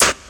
• 80's Dirty Hip-Hop Snare Single Hit G Key 384.wav
Royality free acoustic snare tuned to the G note. Loudest frequency: 4656Hz